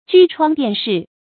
驹窗电逝 jū chuāng diàn shì
驹窗电逝发音